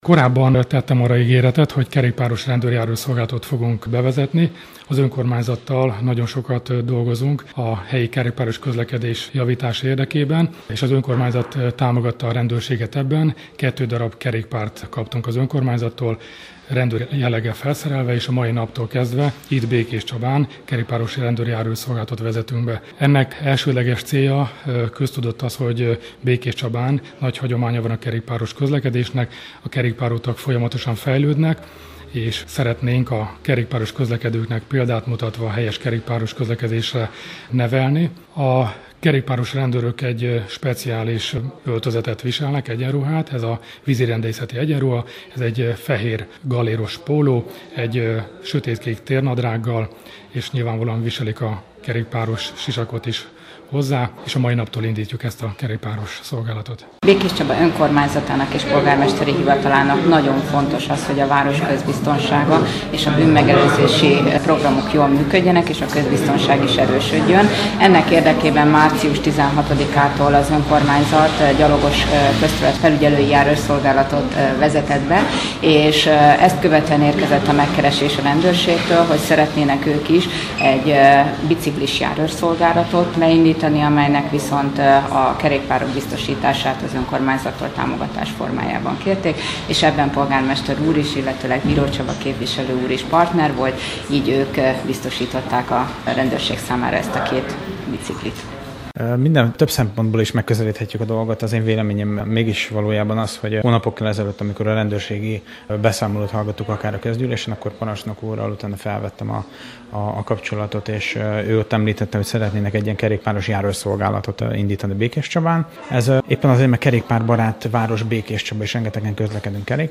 Sajtótájékoztatót tartott a Békés Megyei Rendőr-főkapitányság a kerékpáros járőrszolgálat elindításáról. Elsősorban megelőzési céllal vezetik be a szolgálatot, amely megalakítását az önkormányzat teljes mértékben támogatta. A sajtótájékoztatón részt vett Szarvas Péter polgármester valamint Dr. Bede Sándor rendőrkapitány is.